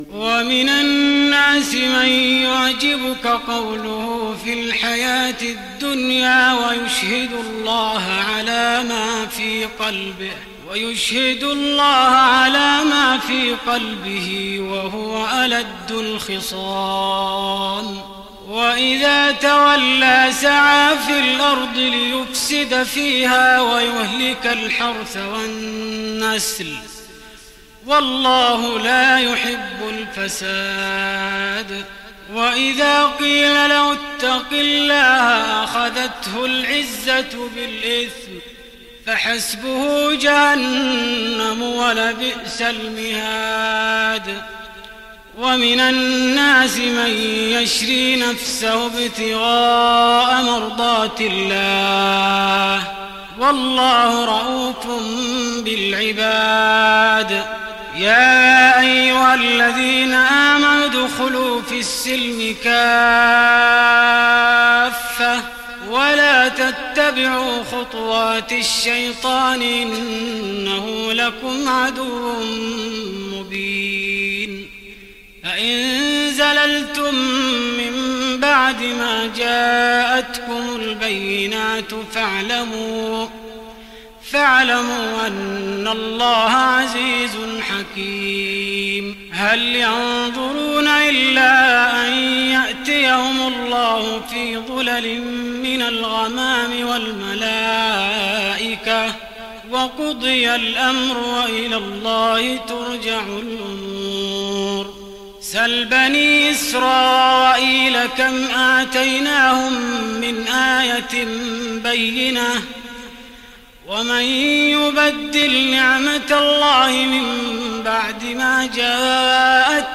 تهجد رمضان 1415هـ من سورة البقرة (204-252) Tahajjud night Ramadan 1415H from Surah Al-Baqara > تراويح الحرم النبوي عام 1415 🕌 > التراويح - تلاوات الحرمين